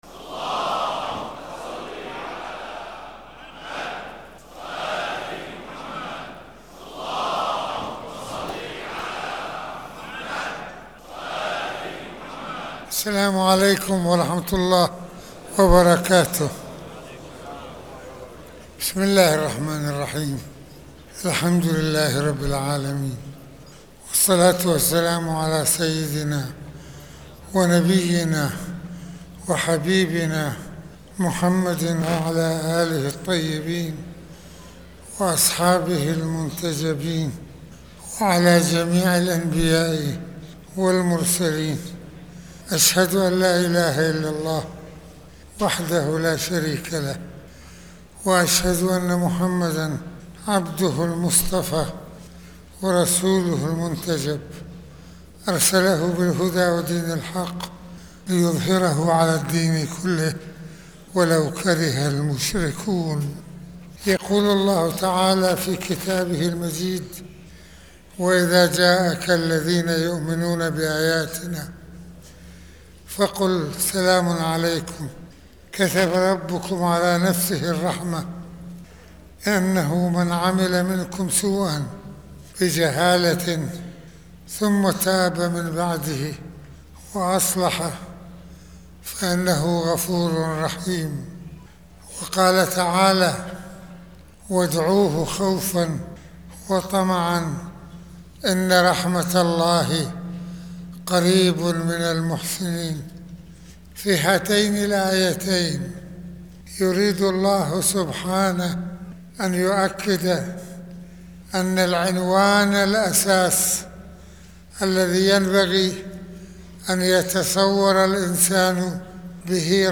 - المناسبة : خطبة الجمعة
المكان : مسجد الإمامين الحسنين(ع)